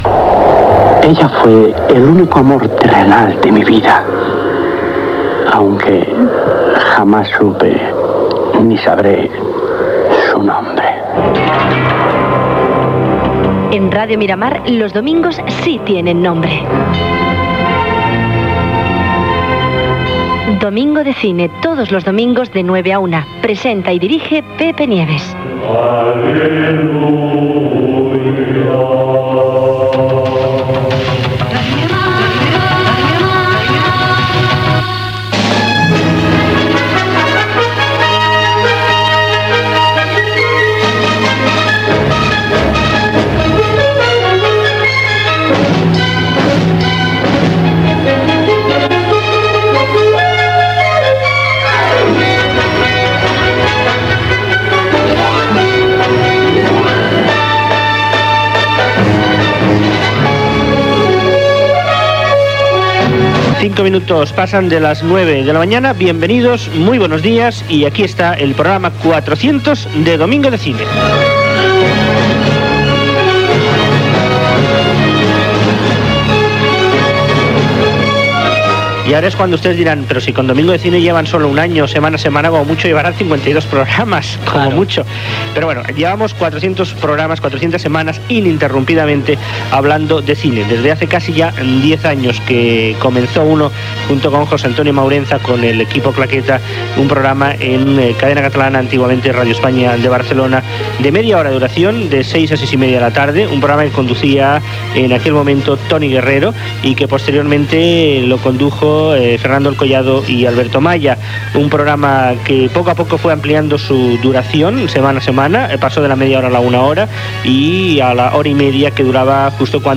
Indicatiu del programa, indicatiu de l'emissora, hora, inici del programa 400 (sumant els espais fets a Cadena Catalana), recordant la història del programa.